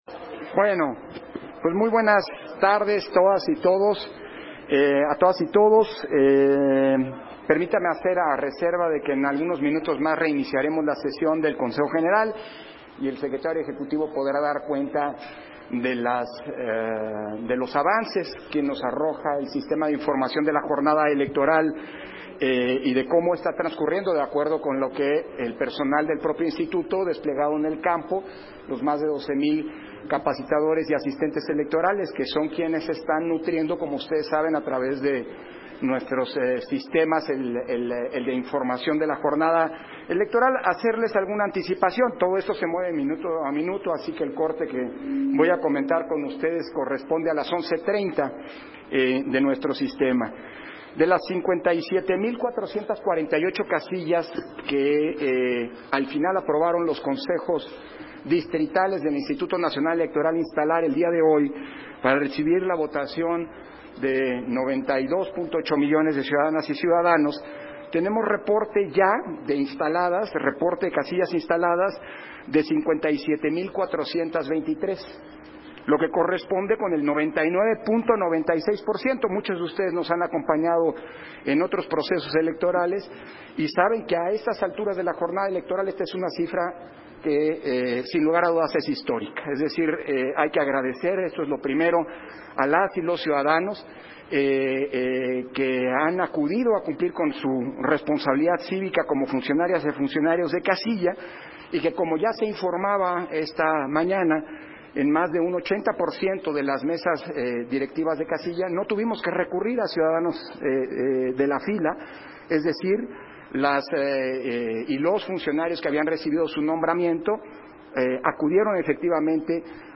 Conferencia de prensa que ofreció Lorenzo Córdova, sobre la jornada de Revocación de Mandato